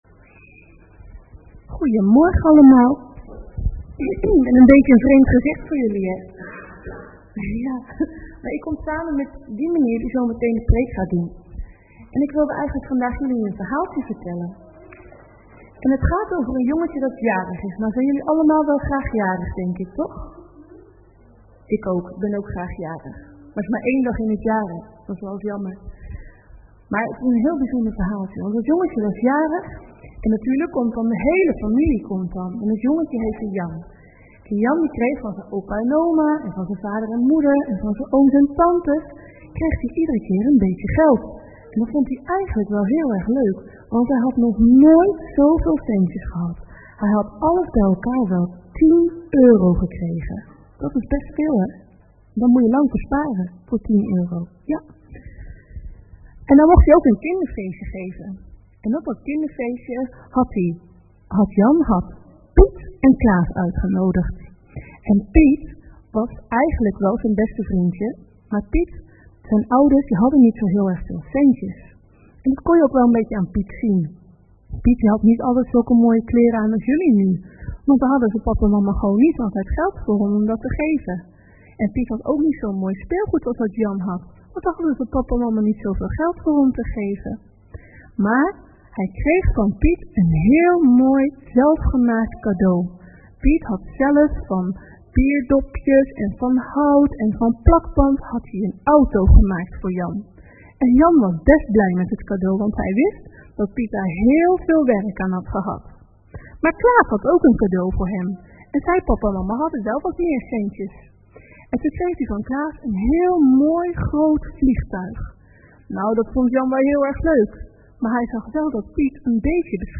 Locatie: ZDA Zwolle